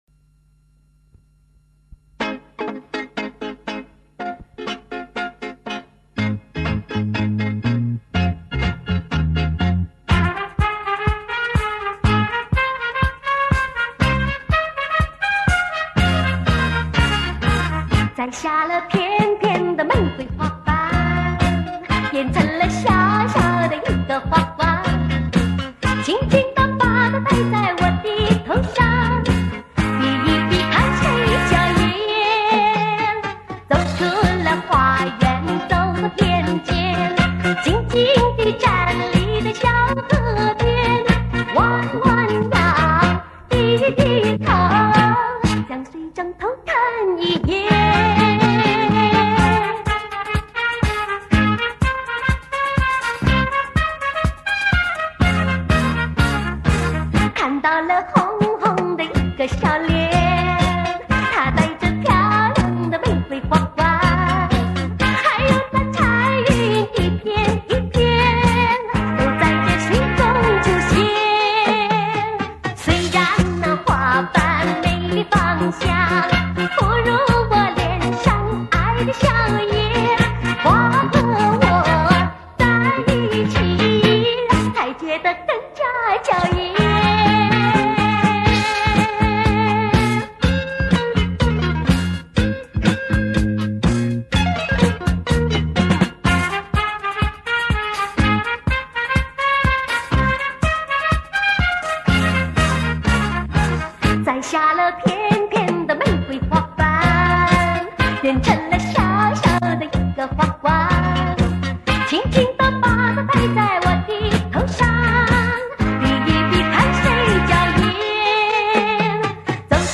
录音带放了近三十年，有些残旧，所幸只有几首歌曲有某些损坏。